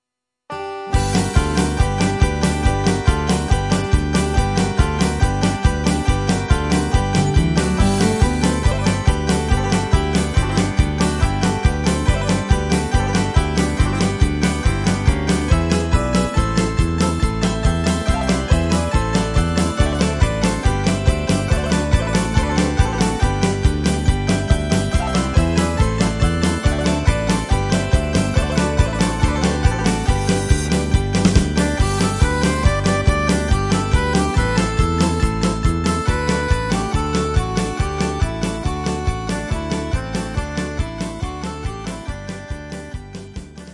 Greek Dance Hasaposerviko-Serviko-2/4